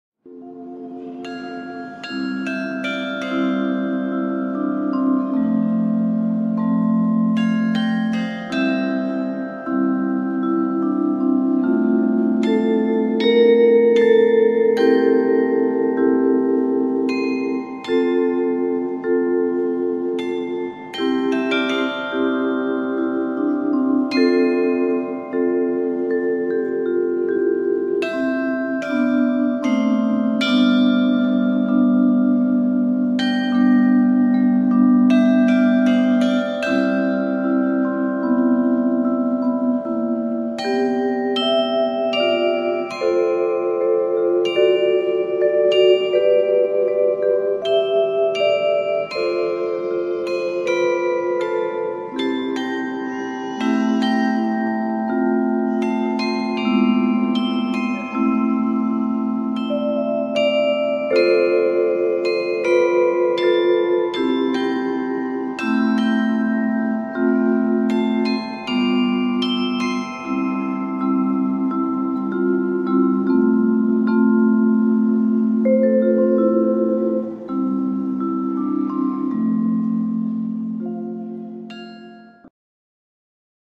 実際の音色
実際の演奏で使った時の音源です。
被災地支援企画『遠い日にしない、あの時』で演奏しました。
そのためか演奏クオリティは低いです・・・
未熟な演奏ですが、カリヨンとビブラフォンの演奏は下記より視聴できます。